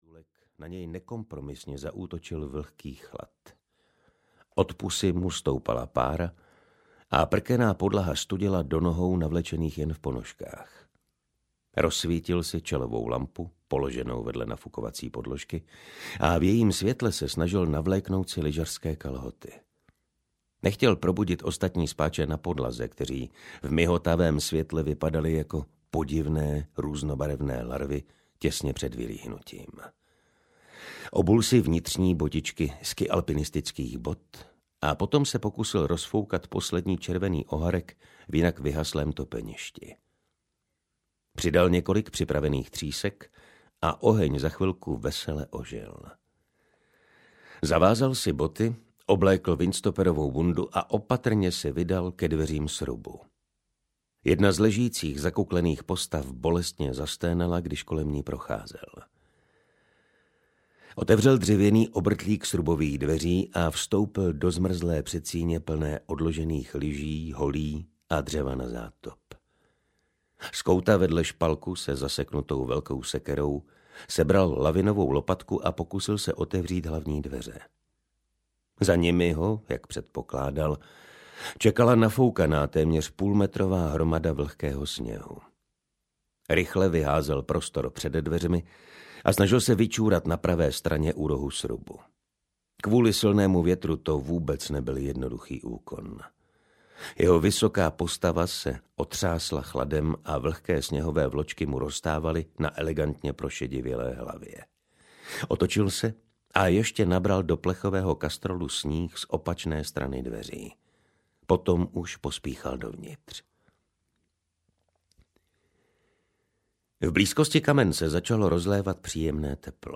Když se kruh uzavřel audiokniha
Ukázka z knihy
kdyz-se-kruh-uzavrel-audiokniha